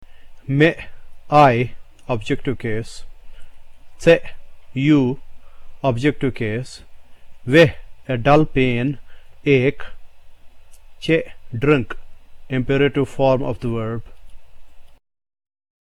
This vowel has the same sound as the letter E in the English word WRECK. Here are some Kashmiri words that use this sound: